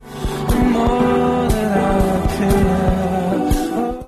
speaks with BATH-broadening
with the schwa quality clearly belonging to the second